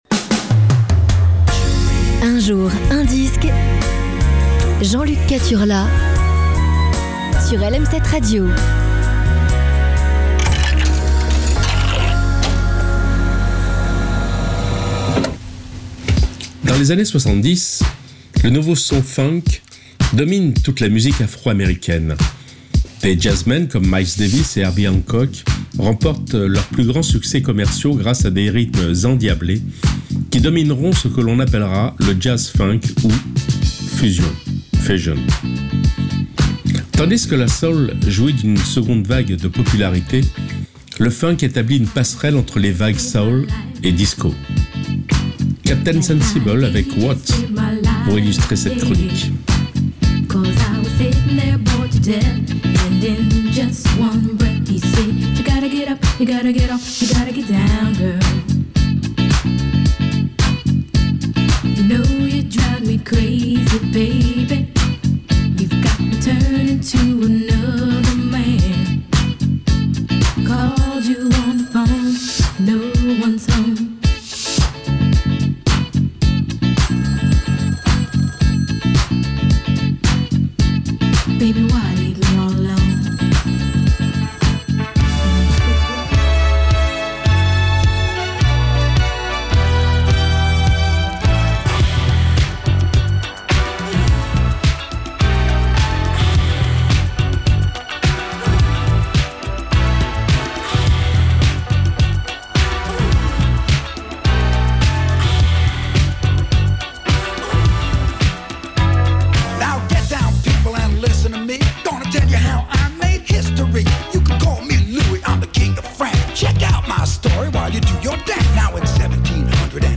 aujourd'hui c'est FUNK